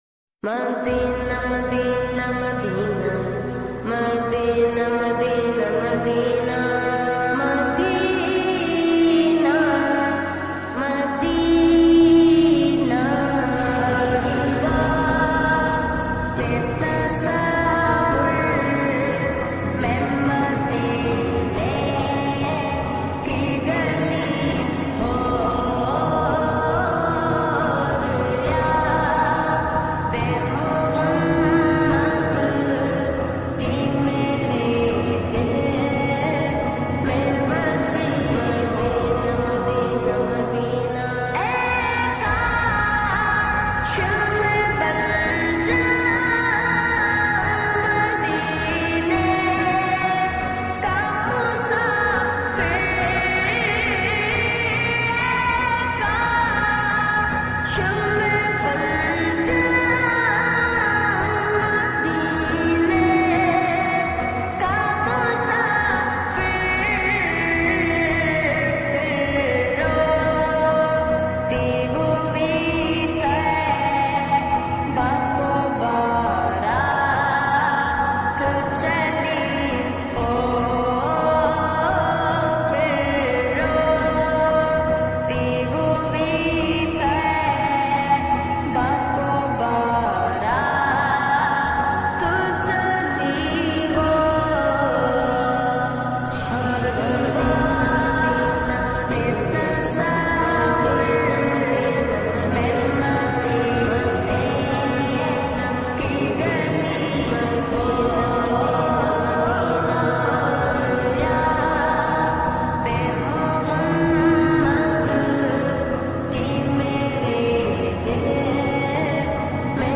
Naat
Slowed Reverb Naat